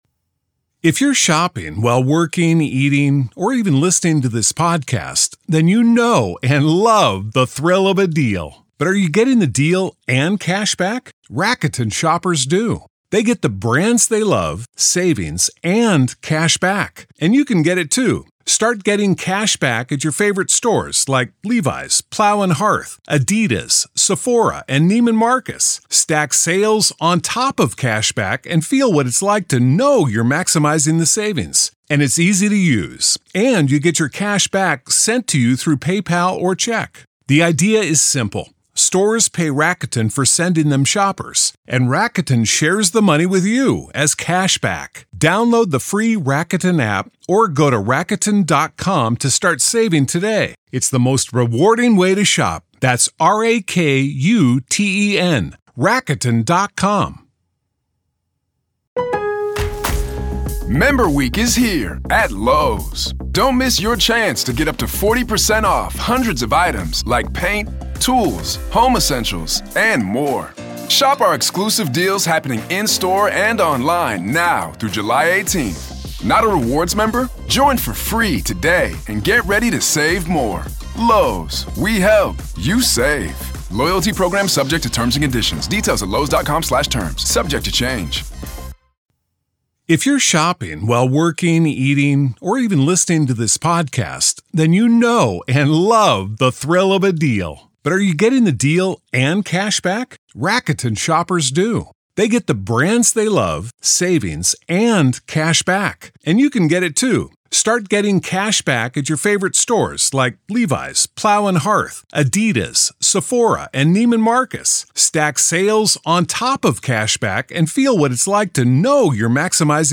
True Crime Today | Daily True Crime News & Interviews / Kohberger's 37-Day Window To Come Up With An Alibi